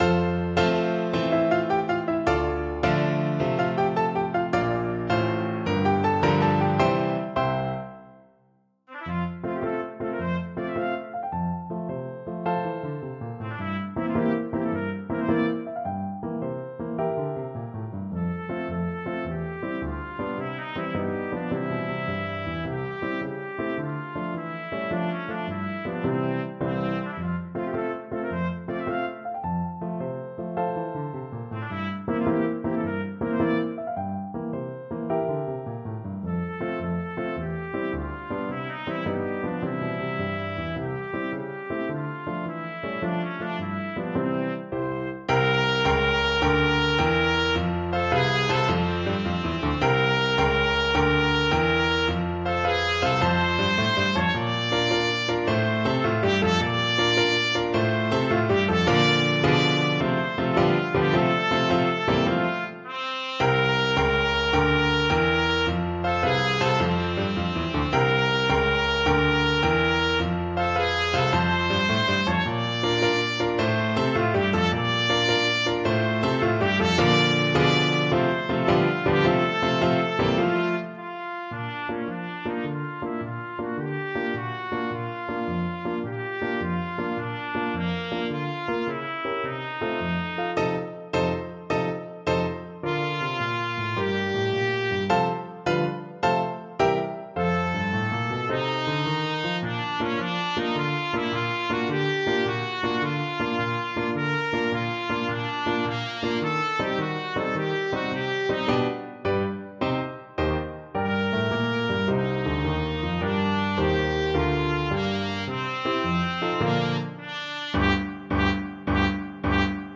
Trumpet
. =106 Tempo de Marcia
6/8 (View more 6/8 Music)
F major (Sounding Pitch) G major (Trumpet in Bb) (View more F major Music for Trumpet )
Arrangement for Trumpet and Piano
Classical (View more Classical Trumpet Music)